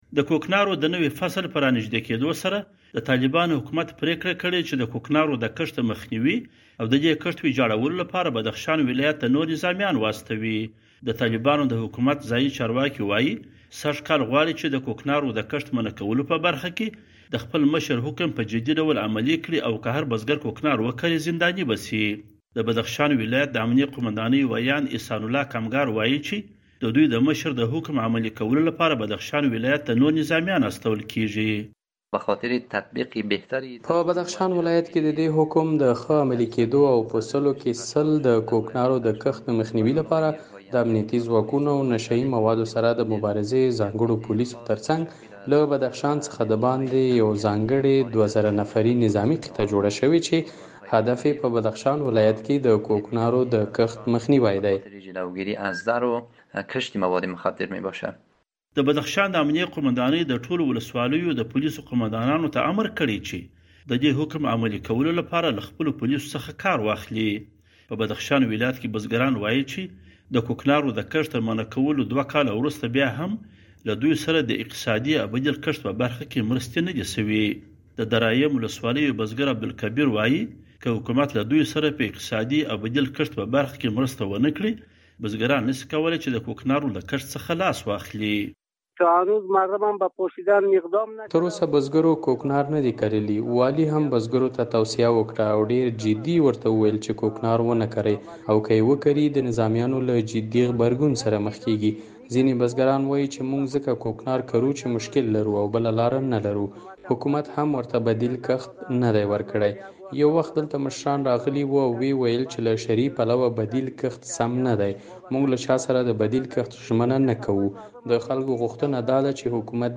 د بدخشان د کوکنارو په اړه راپور